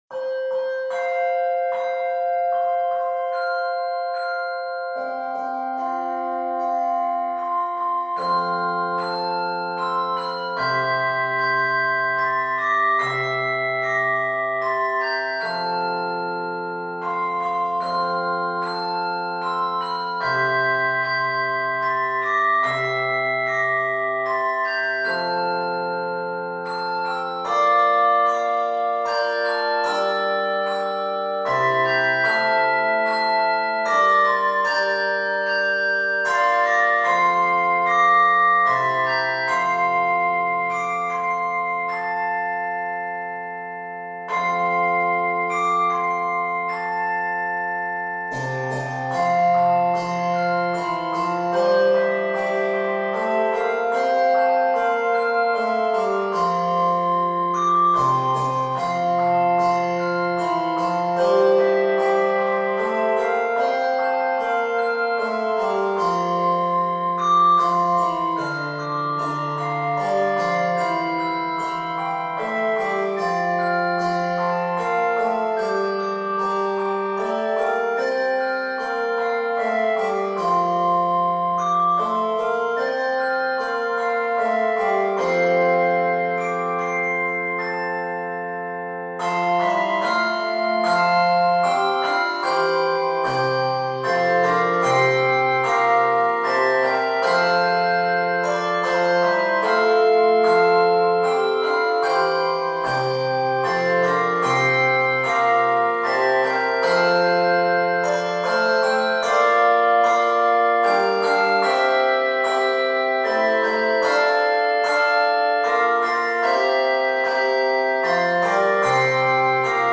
peaceful ending. 80 measures in the key of F Major.